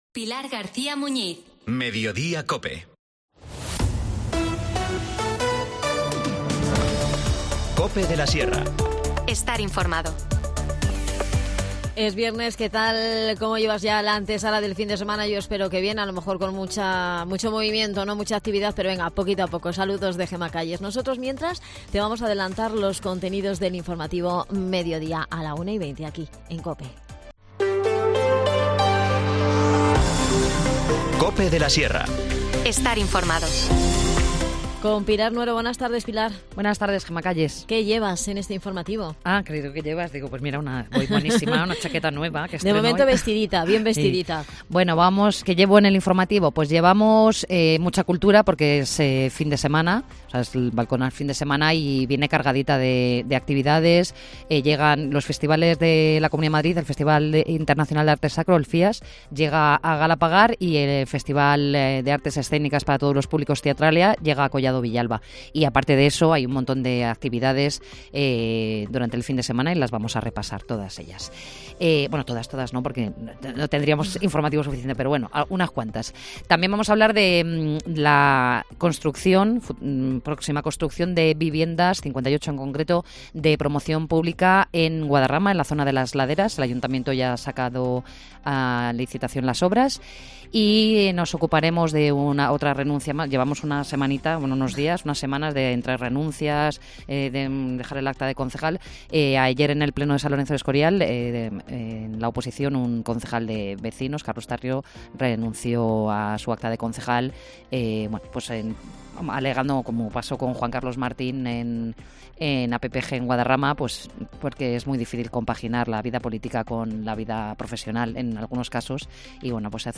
Nos lo cuenta Beatriz Gutiérrez, concejal de Educación.